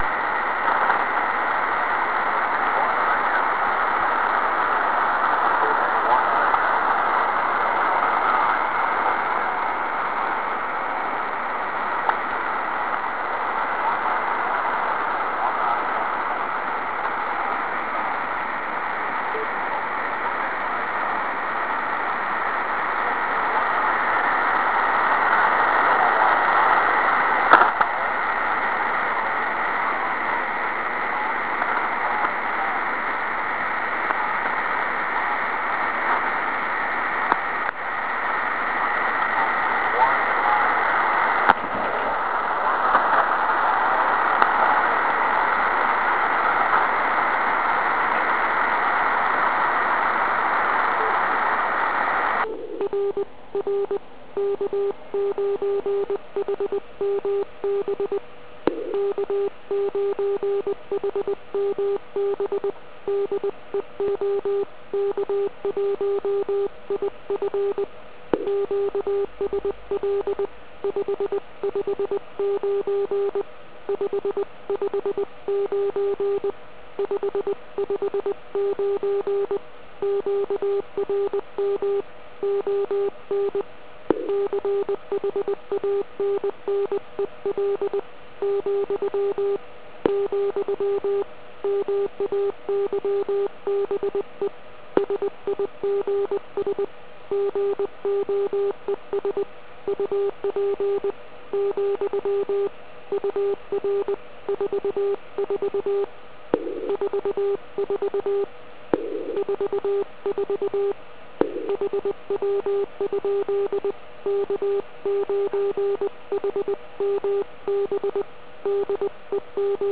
Američani mají povoleno 5 kanálů USB, ale nesmí vysílat CW.